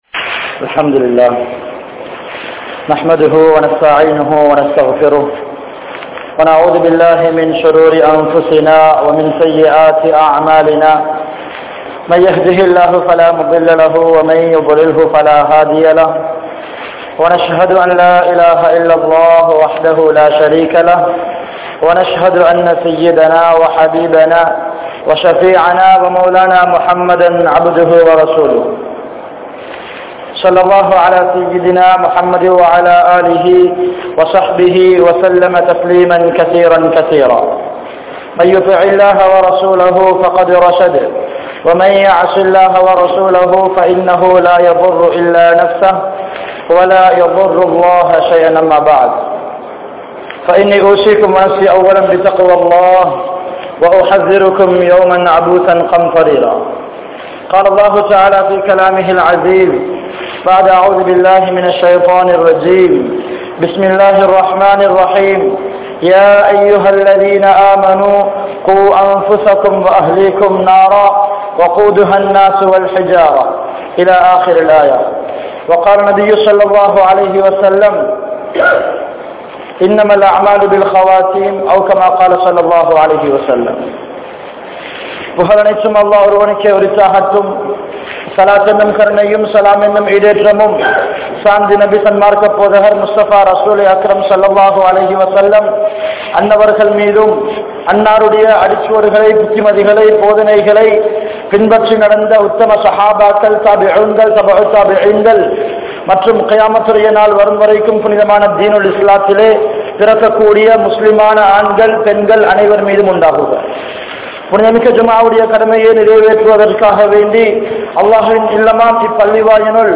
Ramalan Thantha Paadam Enga? (றமழான் தந்த பாடம் எங்கே?) | Audio Bayans | All Ceylon Muslim Youth Community | Addalaichenai